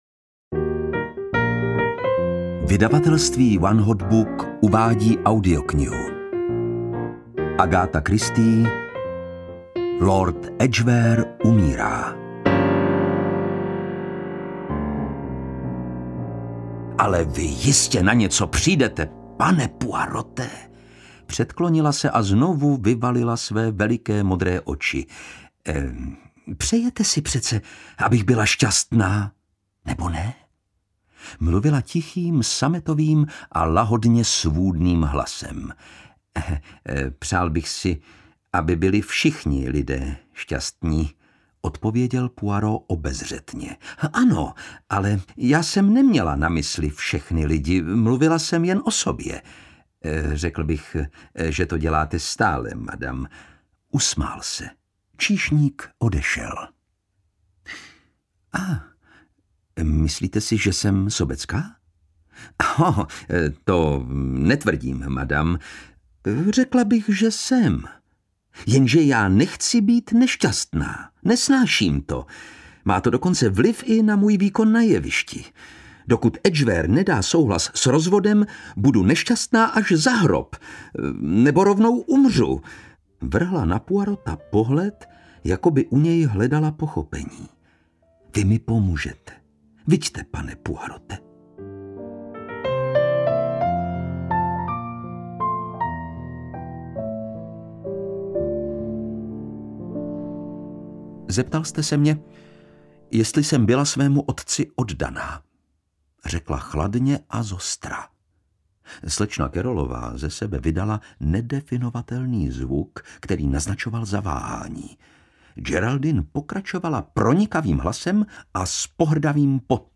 Lord Edgware umírá audiokniha
Ukázka z knihy
• InterpretLukáš Hlavica